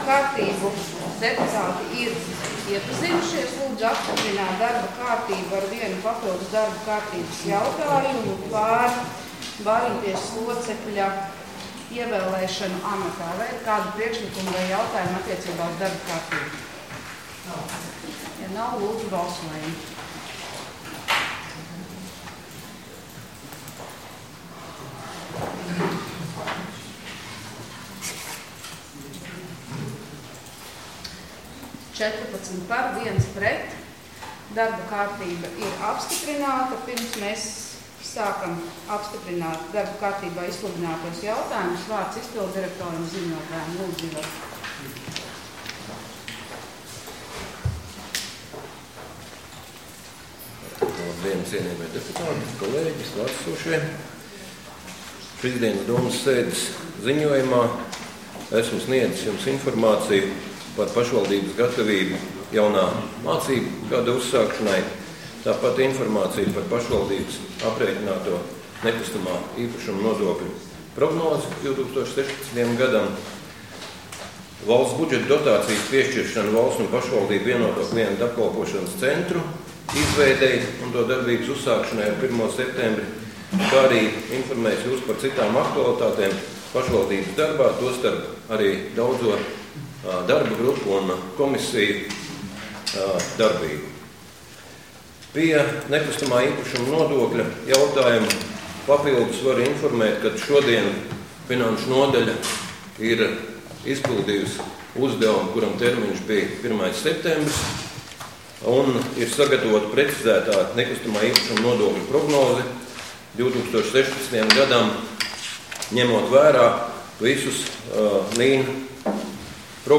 Domes sēde Nr. 15